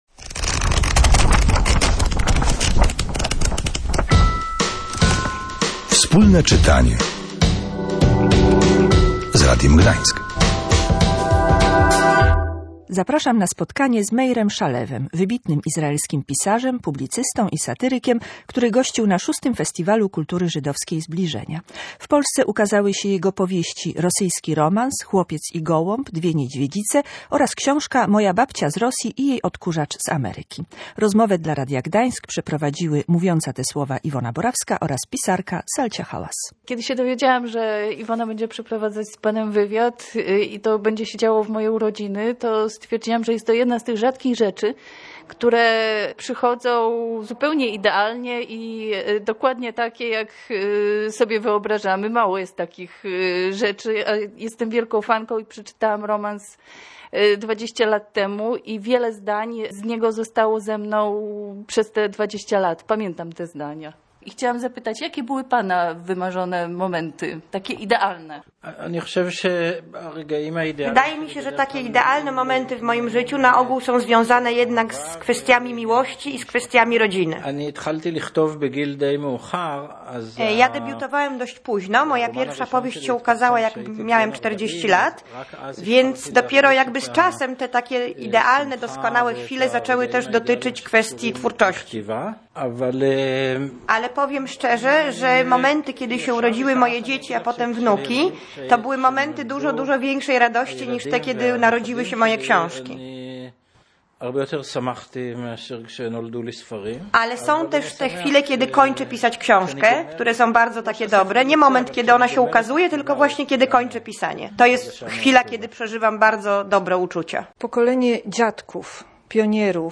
Spotkanie z Meirem Shalevem – wybitnym izraelskim pisarzem, publicystą i satyrykiem, który gościł na VI Festiwalu Kultury Żydowskiej "Zbliżenia".